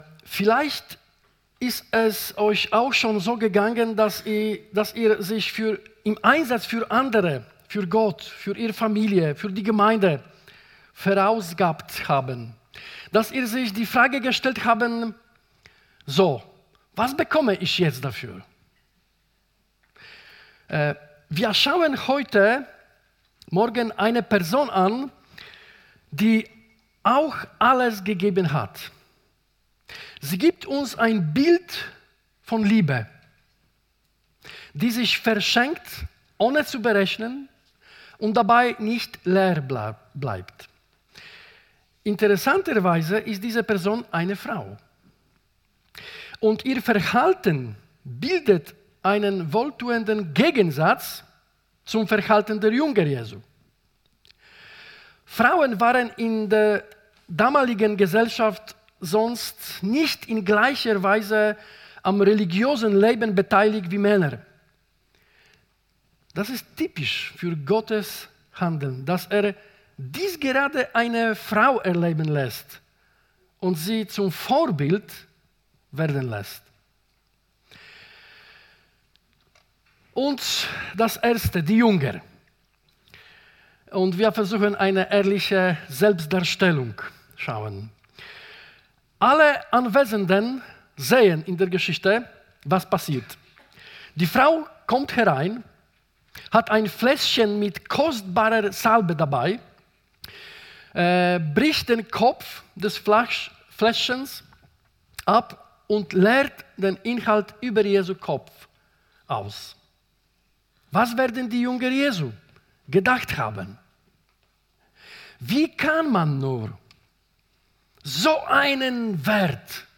Predigt 8. März 2026